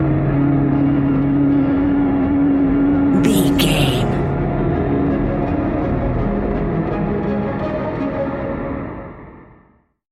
Ionian/Major
C♯
dark ambient
EBM
synths
instrumentals